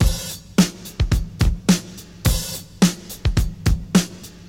107 Bpm Drum Loop Sample A# Key.wav
Free breakbeat - kick tuned to the A# note.
.WAV .MP3 .OGG 0:00 / 0:05 Type Wav Duration 0:05 Size 776,22 KB Samplerate 44100 Hz Bitdepth 16 Channels Stereo Free breakbeat - kick tuned to the A# note.
107-bpm-drum-loop-sample-a-sharp-key-kWr.ogg